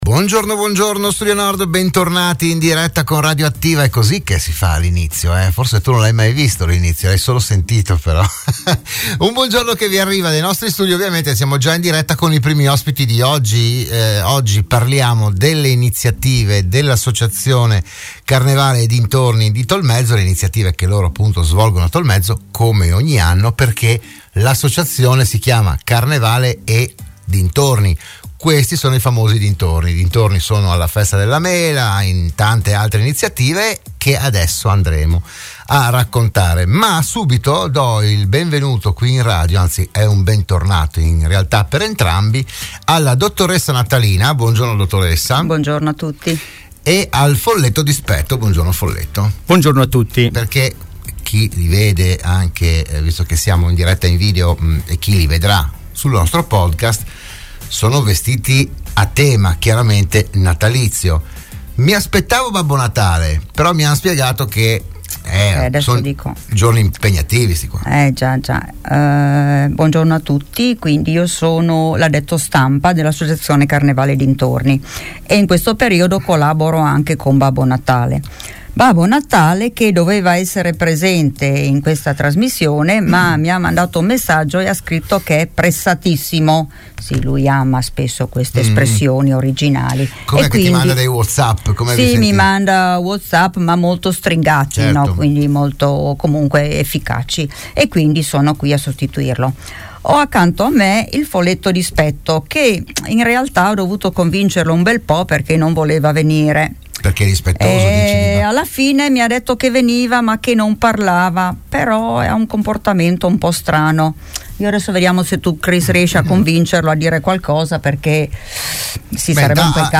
Di tutto questo si è parlato oggi a “ RadioAttiva “, la trasmissione del mattino di Radio Studio Nord